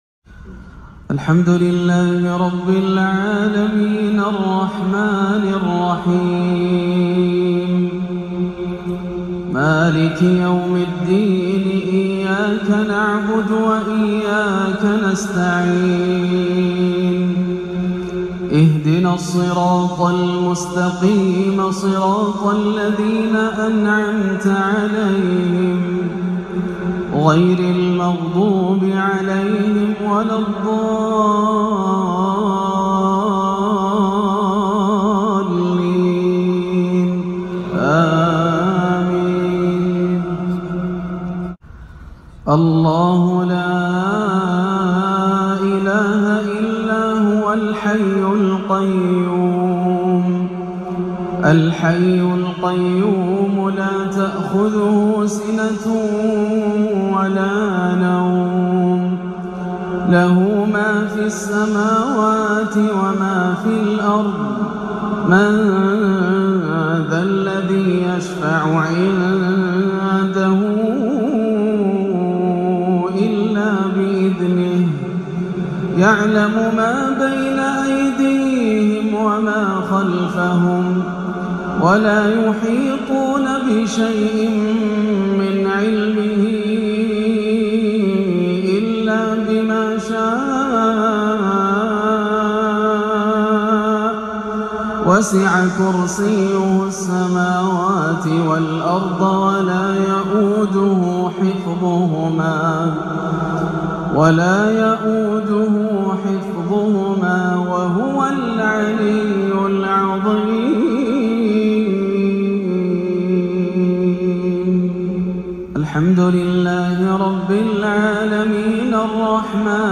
بترتيل فريد ومؤثر للآسر - أجمل مغربية لعام 1438هـ - 7-7-1438 > عام 1438 > الفروض - تلاوات ياسر الدوسري